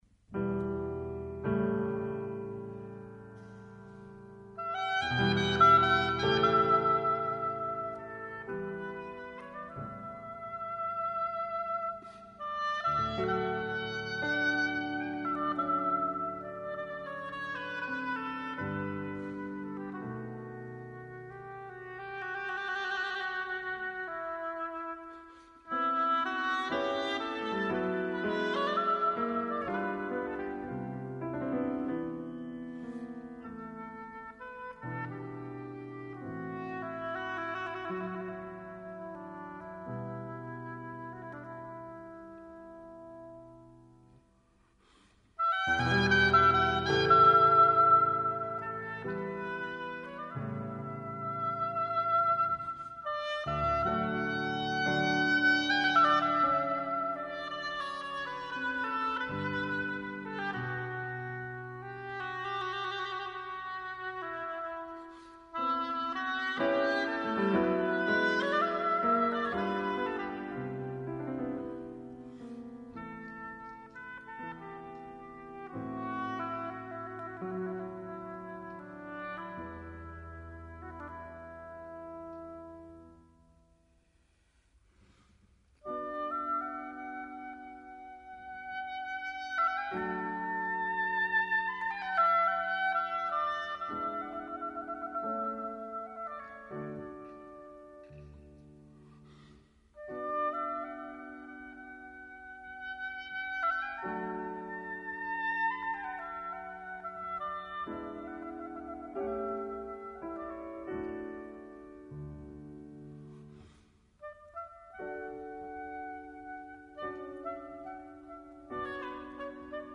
Велики мађарски композитор Бела Барток компоновао је шест гудачких квартета и сви су међу његовим ремек-делима.